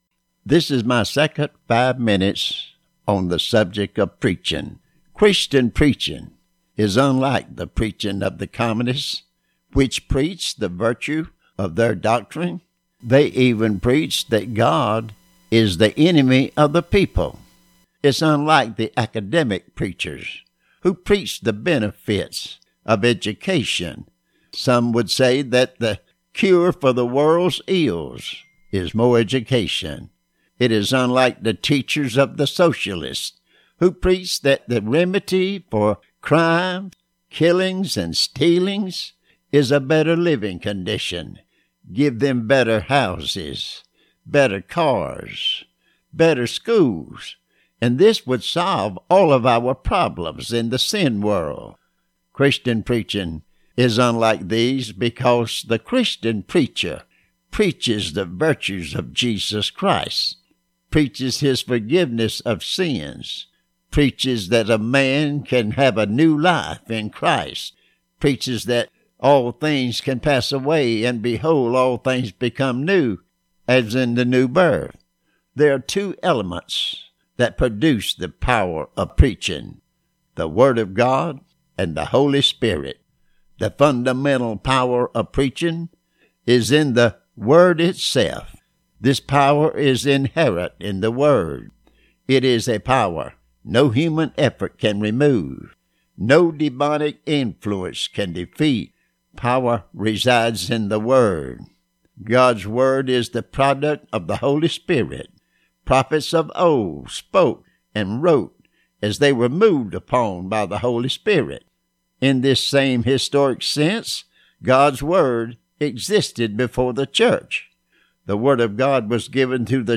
Pentecostal Preaching Part 2